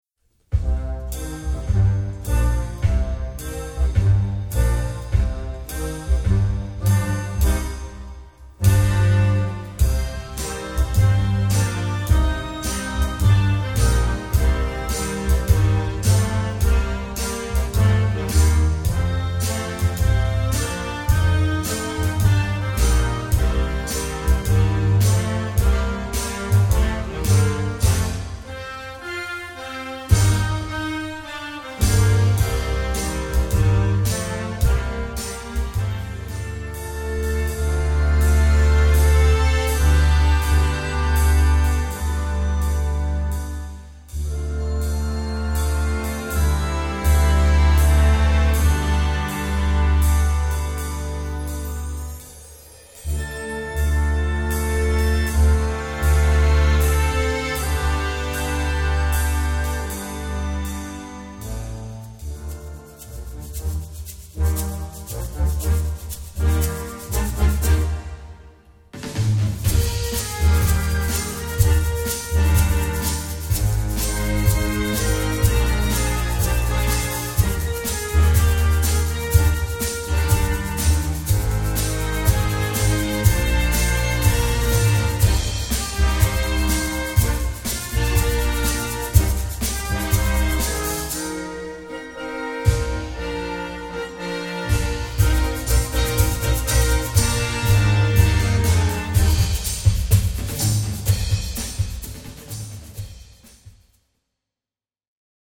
Gattung: für flexibles Jugendblasorchester
Besetzung: Blasorchester